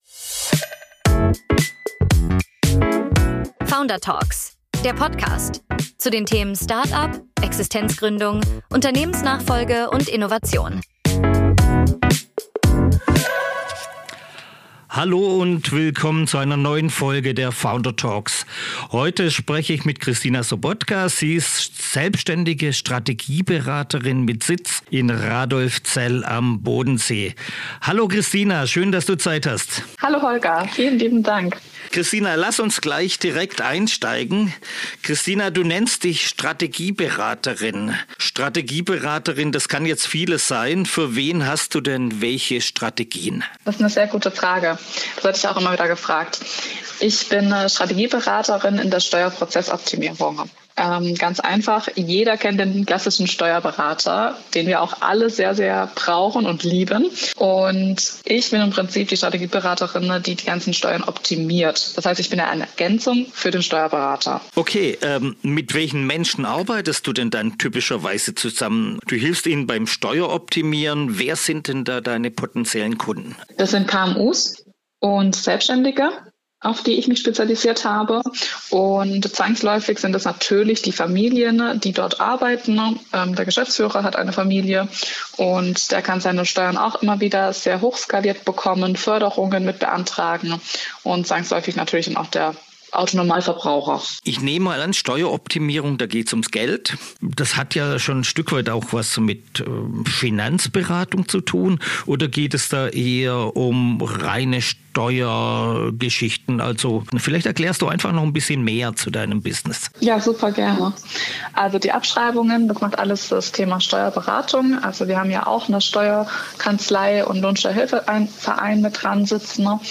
Founder Talk